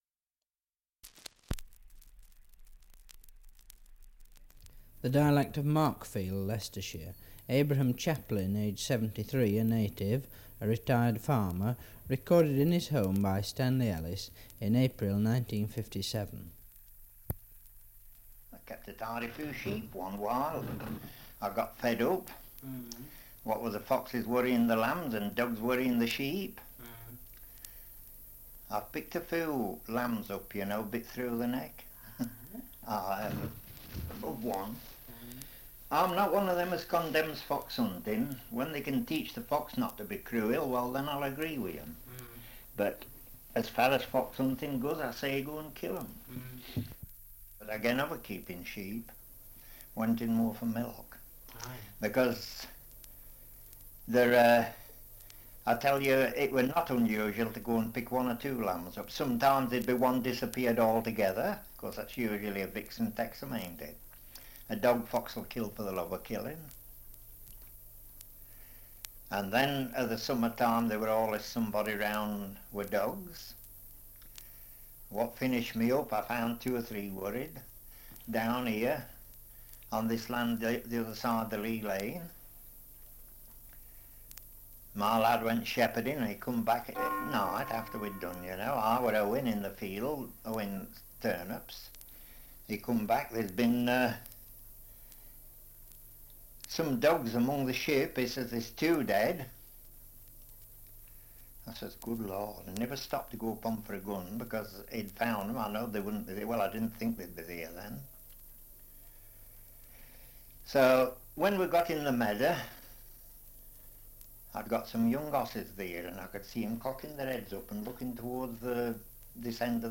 Survey of English Dialects recording in Markfield, Leicestershire
78 r.p.m., cellulose nitrate on aluminium